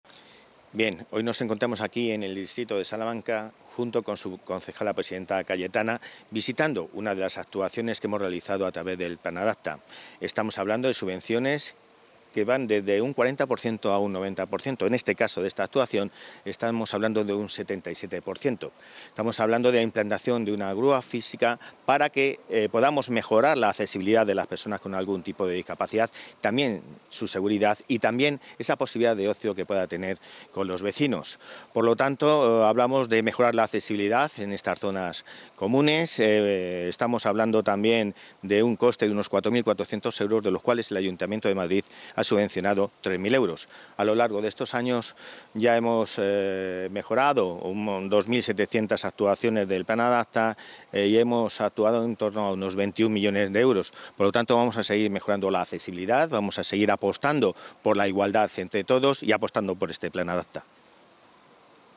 Intervención del delegado de Políticas de Vivienda y presidente de la Empresa Municipal de la Vivienda y Suelo (EMVS Madrid), Álvaro González, durante su visita a una actuación del Plan Adapta en una piscina de Salamanca, le acompañaCayetana Hernández de la Riva,
AUDIO-Intervencion-Alvaro-Gonzalez-Plan-adapta-grua-piscina-comunitaria.mp3